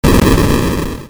5-3.爆発（ボーン！）
ちょっと軽めの音ですが、「ボーン！」と爆発する音です。
boon.mp3